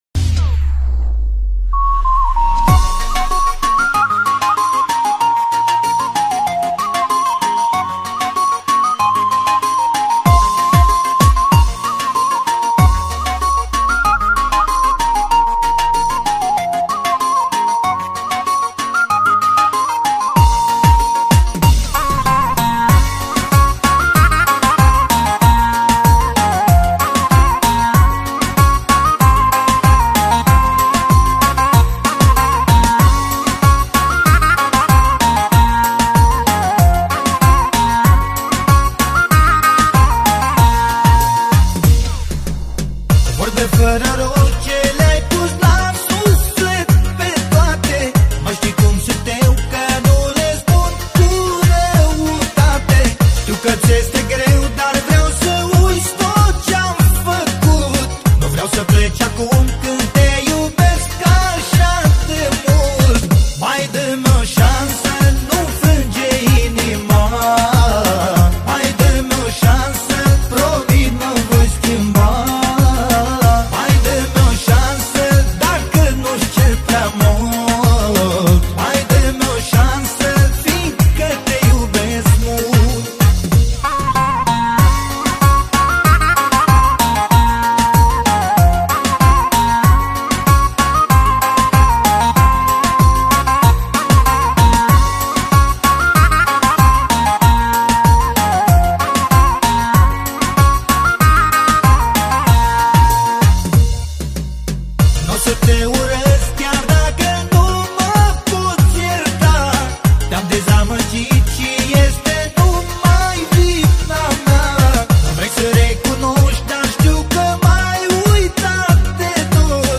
Manele Vechi